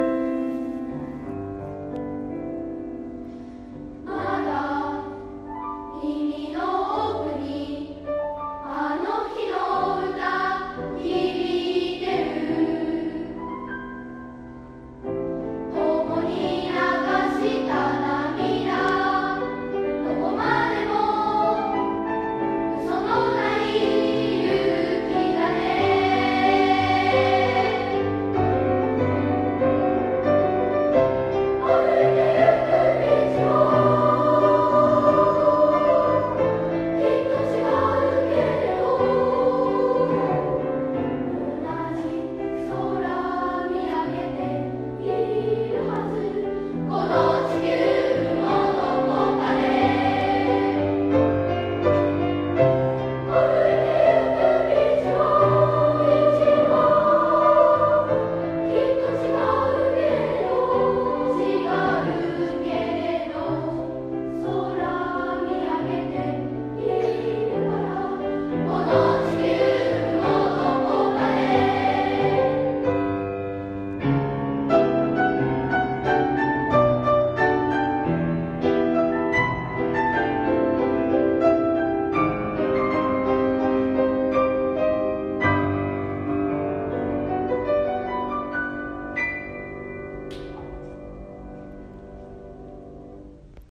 プレ発表　連合音楽会
音楽朝会で５年生が
合唱曲「この地球のどこかで」